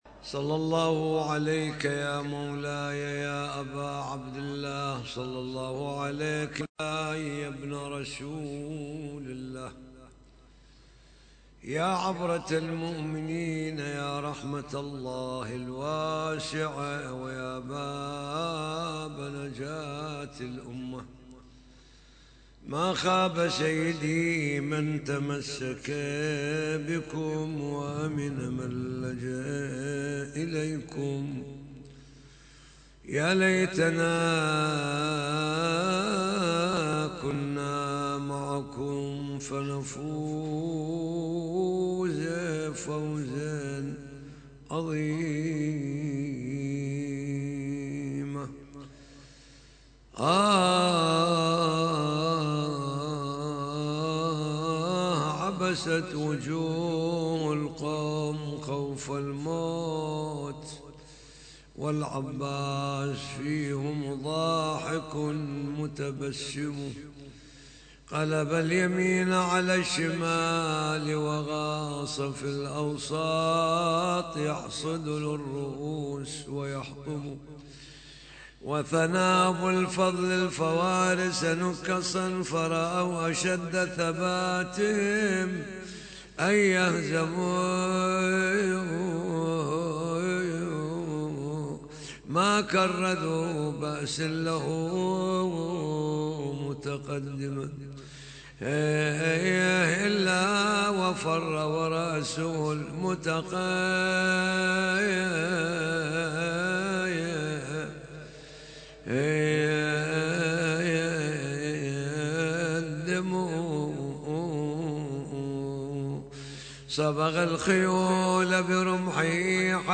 محاضرة ليلة 26 جمادى الأولى